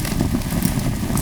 embers.wav